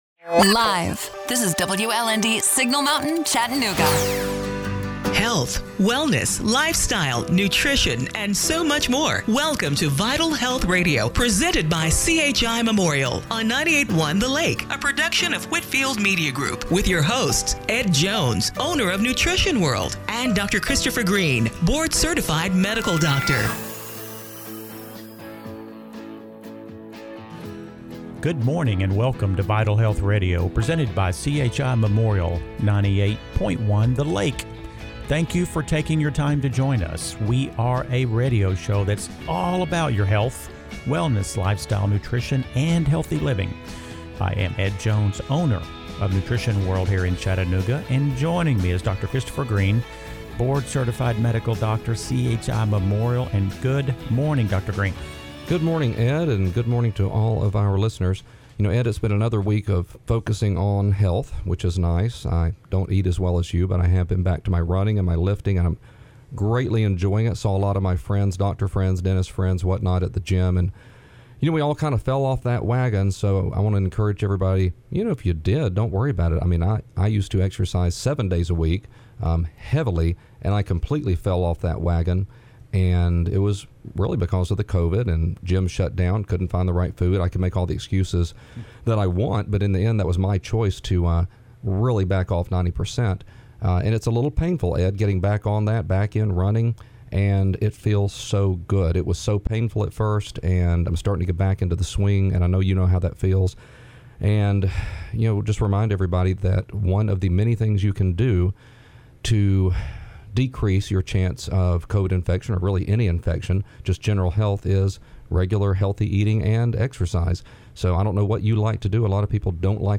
December 6, 2020 – Radio Show - Vital Health Radio